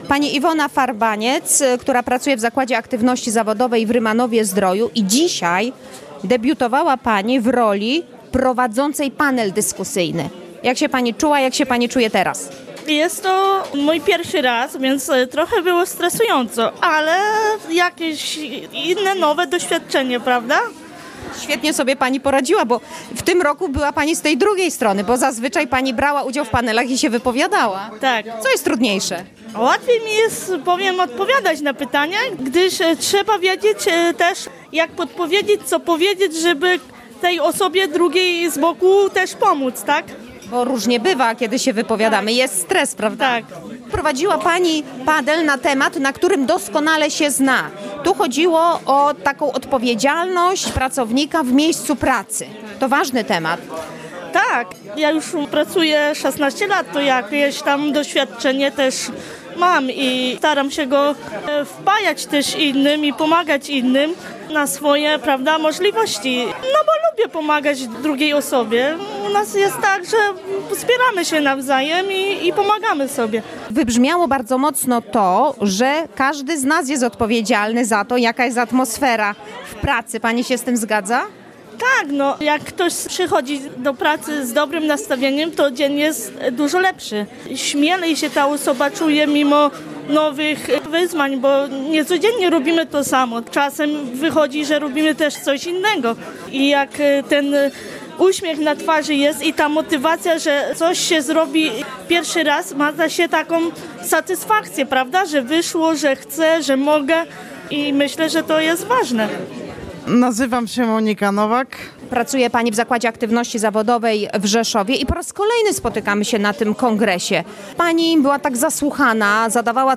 A zaczniemy od rozmów z uczestnikami Kongresu.